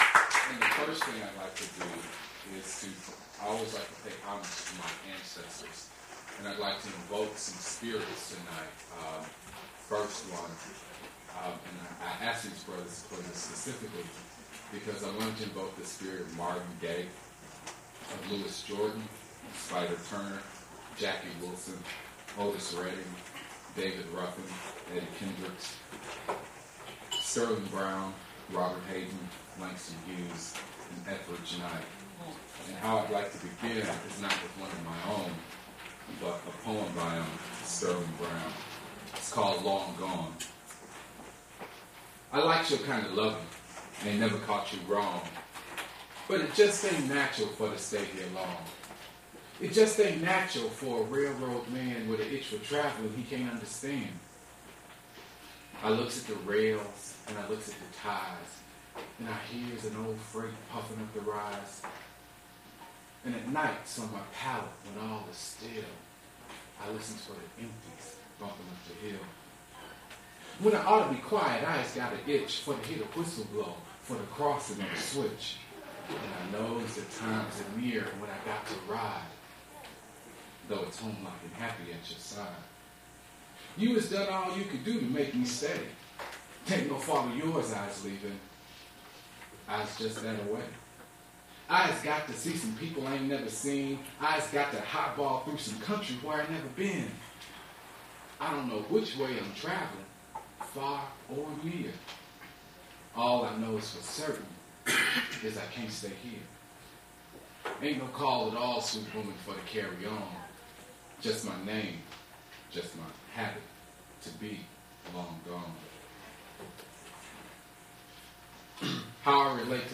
mp3 edited access file was created from unedited access file which was sourced from preservation WAV file that was generated from original audio cassette. Language English Identifier CASS.737 Series River Styx at Duff's River Styx Archive
Accompanying percussionists and other musicians are not introduced.